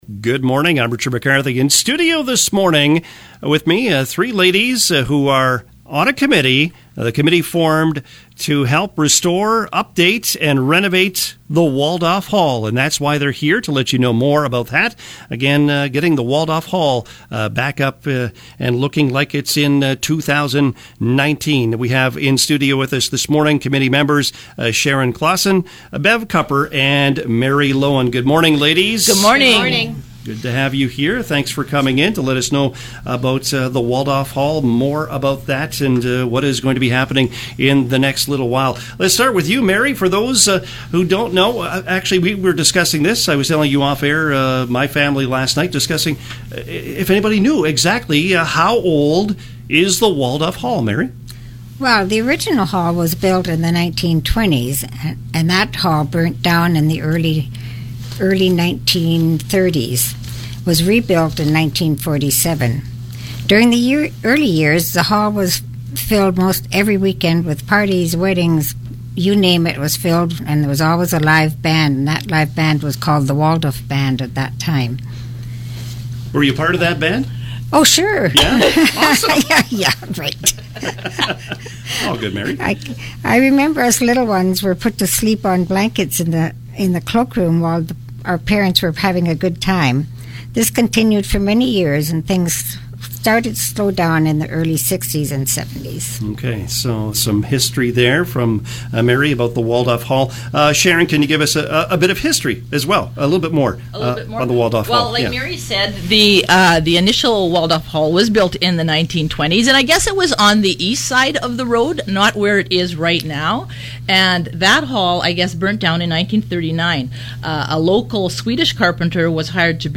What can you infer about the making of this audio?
they were guests on the CKDR Morning Show today